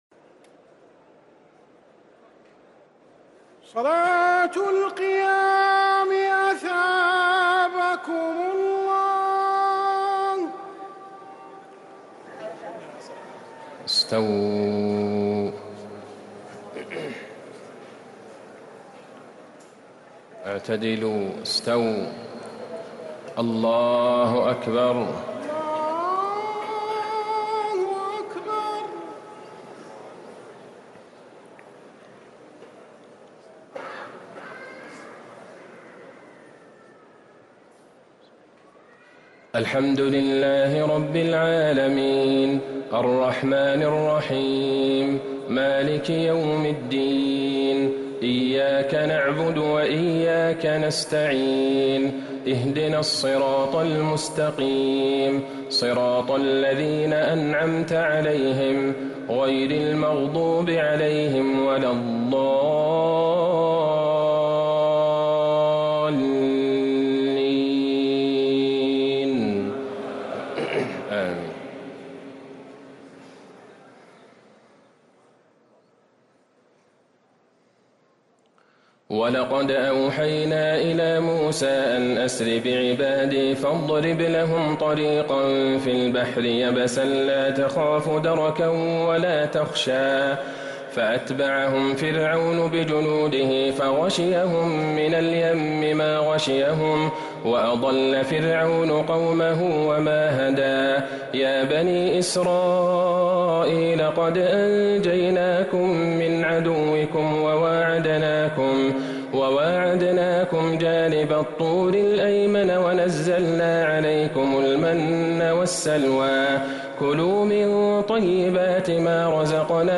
تهجد ليلة 21 رمضان 1444هـ من سورتي طه (77-135) و الأنبياء (1-73) | Tahajjud 21st night Ramadan 1444H Surah Taha and Al-Anbiya > تراويح الحرم النبوي عام 1444 🕌 > التراويح - تلاوات الحرمين